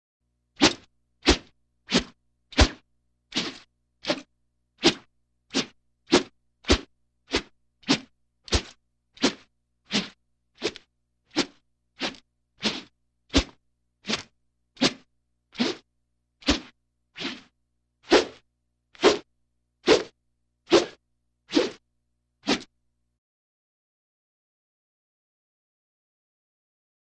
Multiple Racquetball Racket Swishes.